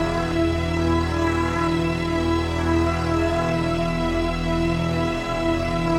Index of /musicradar/dystopian-drone-samples/Non Tempo Loops
DD_LoopDrone2-D.wav